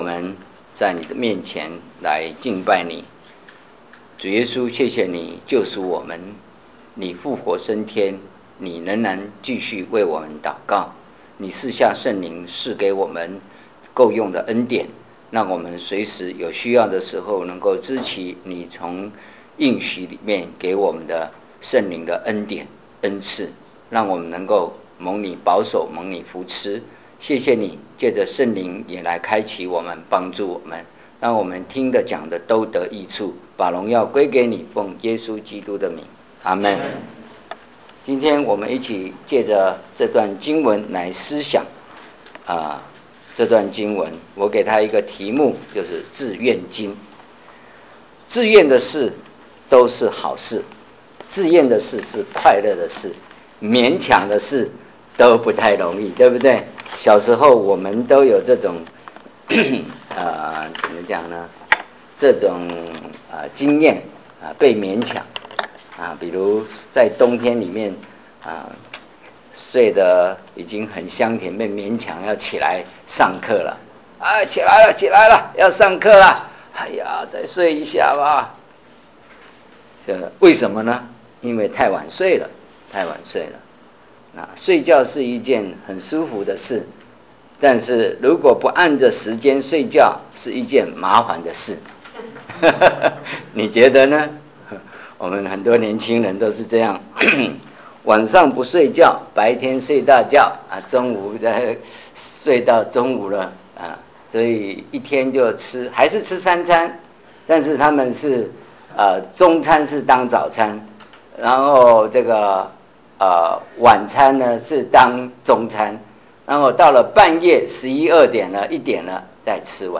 所有布道录音现都已转换成MP3格式，这样能用本网站内置播放器插件播放。